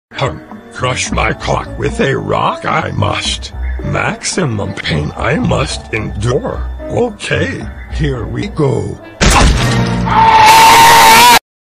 Play Yoda Screaming - SoundBoardGuy
Play, download and share yoda screaming original sound button!!!!
yoda-screaming.mp3